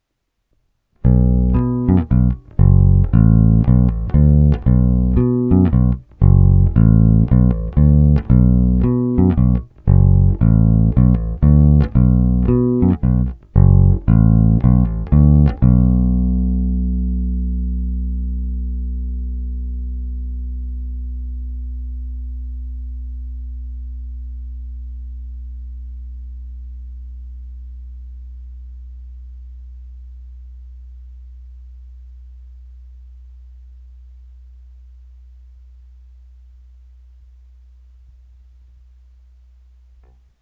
Ne tak zvonivý, ale zato parádně pevný, tučný, a to i s přihlédnutím k tomu, že jsem basu dostal s hlazenkami s nízkým tahem.
Povinné jsou s plně otevřenou tónovou clonou rovnou do zvukovky a jen normalizovány, bonusové jsou se simulacemi aparátu.
Hra nad snímačem